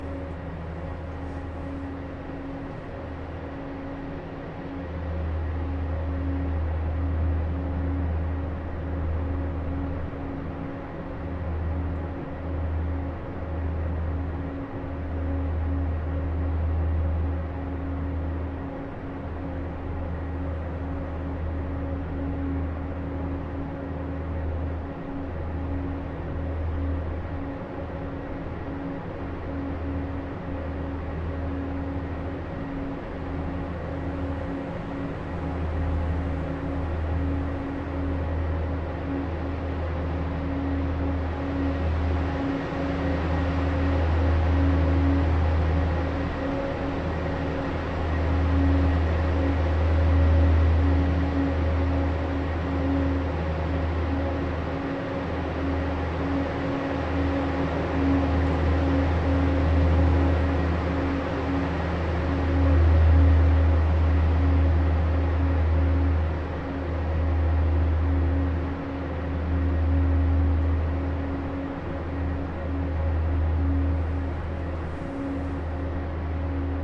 车库 " 大厅
描述：录音是在德国莱比锡市中心的一个停车场的楼上大厅进行的，一个穿着高跟鞋的女人穿过地板的脚步声。
标签： 环境 城市 回声 女性 现场记录 脚步声 车库 大厅 室内 大房间
声道立体声